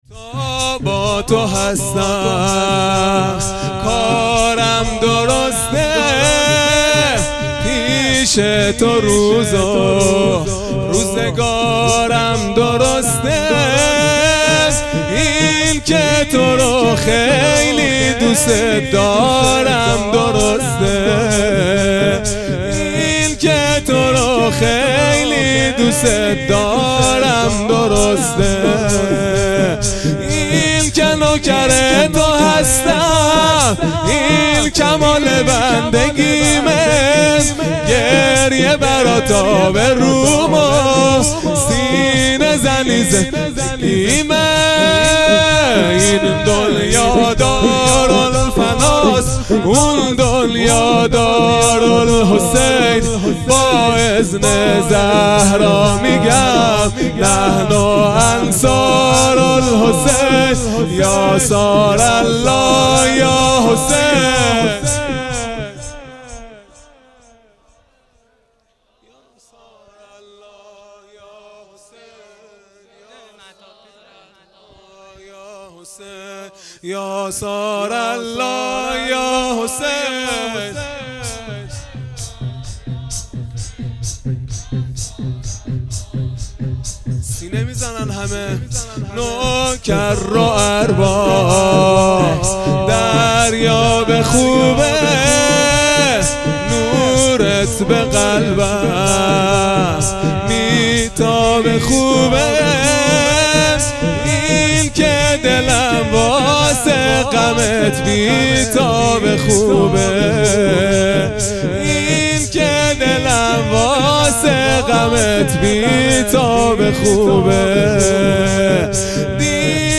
شور | تا با تو هستم کارم درسته | چهارشنبه ۲۰ مرداد ۱۴۰۰
دهه اول محرم الحرام ۱۴۴۳ | شب سوم | چهارشنبه ۲۰ مرداد ۱۴۰۰